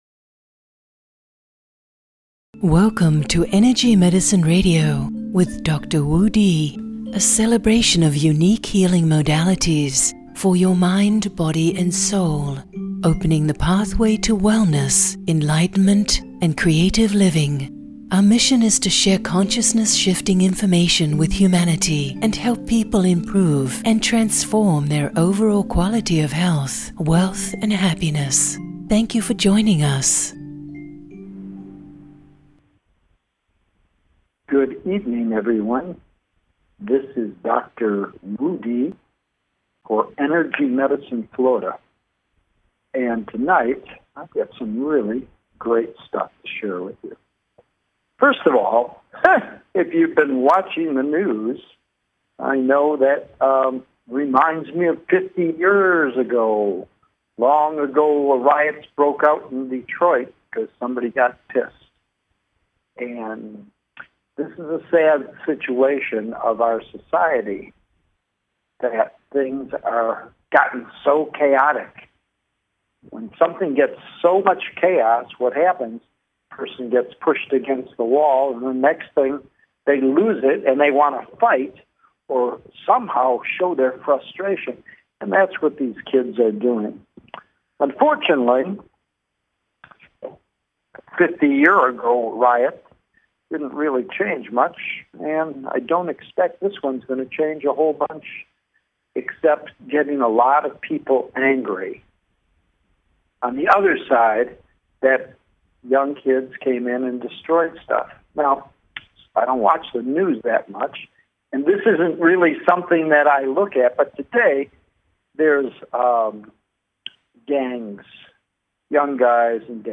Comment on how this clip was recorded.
Live on Air: Every 1st and 3rd Tuesday 8-9pm EST (5-6pm PST) USA Join me as I support expansion into your full potential while paving the way to profound transformation and healing.